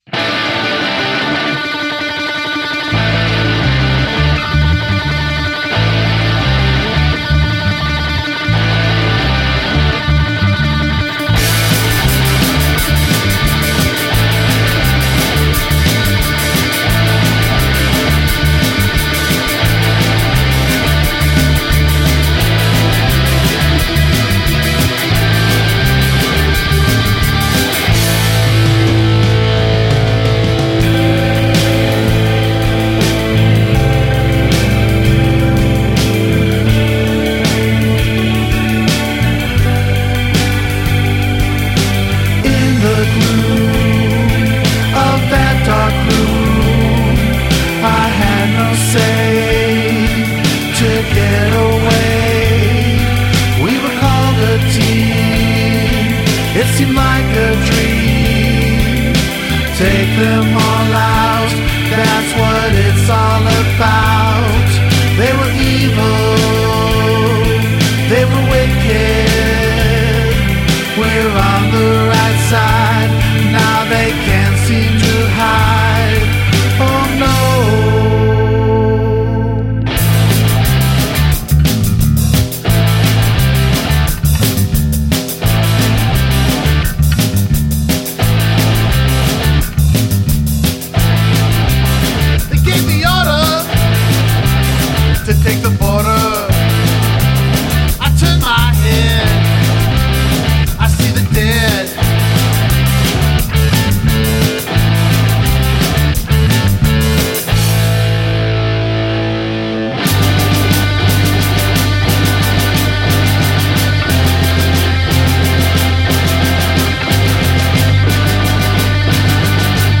blend an array of alternative rock styles
“modern classic rock” sound